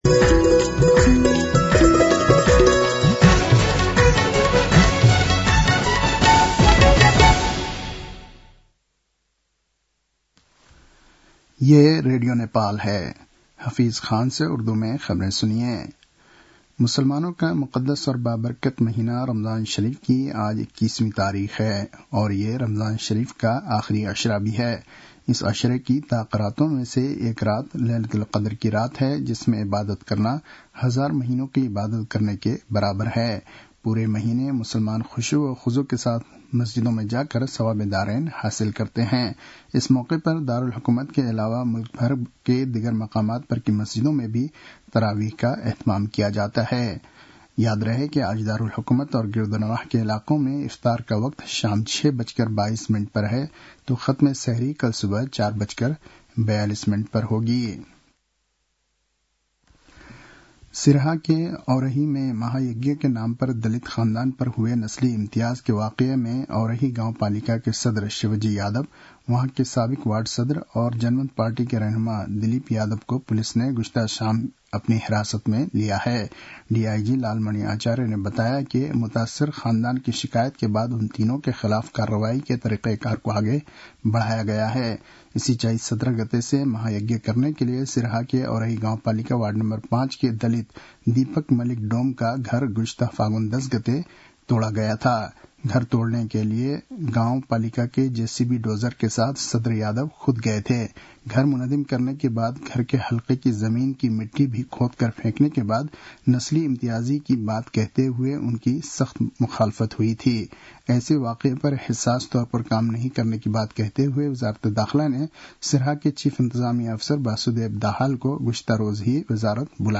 उर्दु भाषामा समाचार : ९ चैत , २०८१